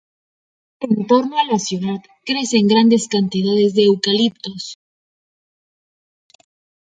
tor‧no
/ˈtoɾno/